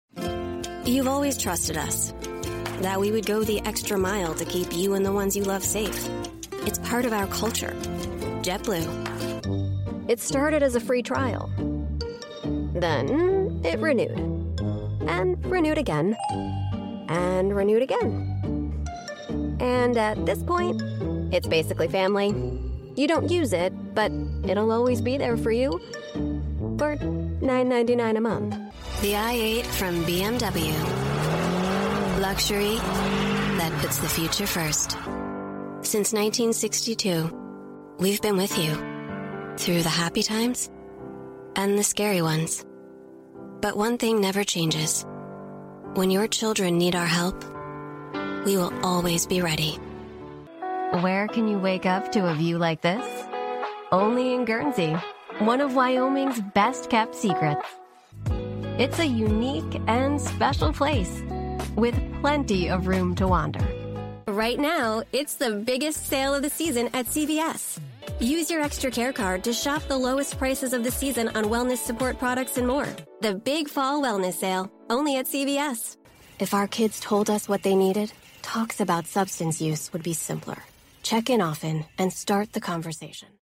Hire Funny Voice Artists
Female